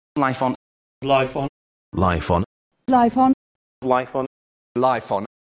But even when the word on is not accentuated, as in the phrase life on earth, it’s likely to keep its full vowel. Here are several speakers saying life on, taken from the phrase life on earth:
You should be able to hear that the instances of and are /ən/, while the instances of on retain their full vowel.